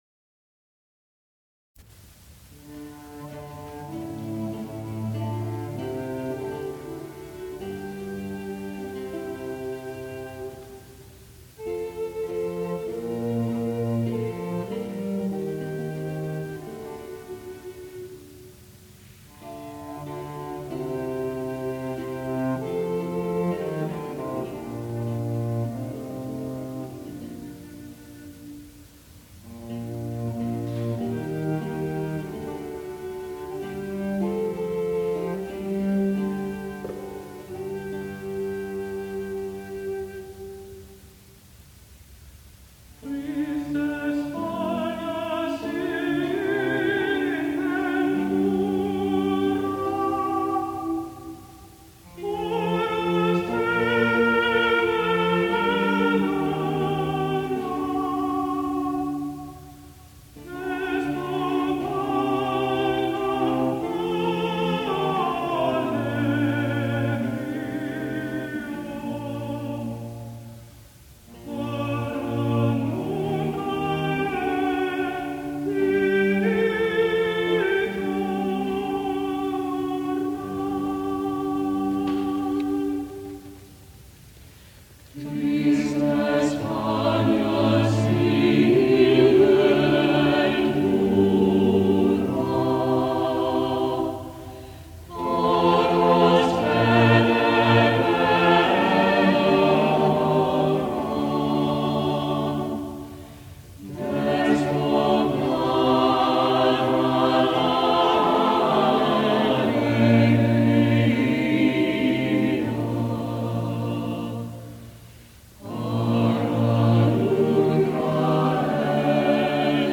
countertenor